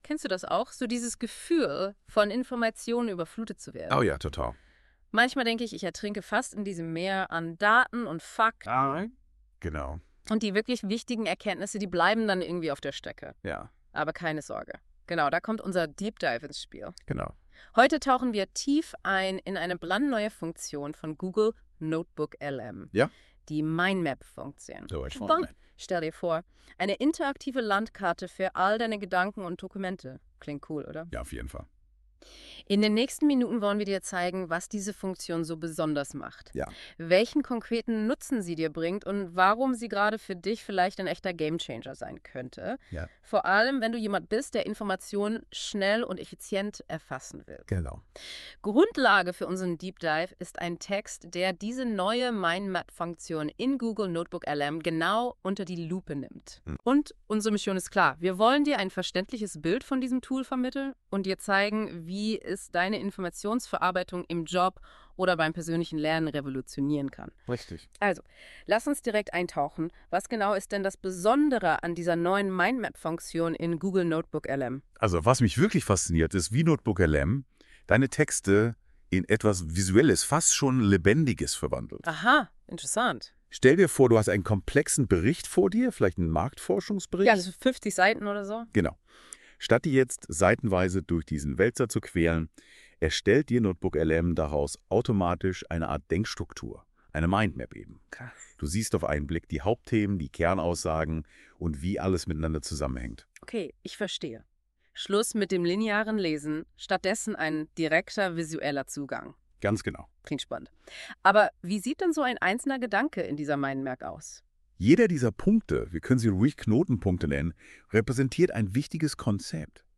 Kurze Zusammenfassung des Beitrags in einer spannenden Audio Unterhaltung. Natürlich mit NotebookLM generiert.